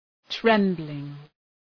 Προφορά
{‘trembəlıŋ}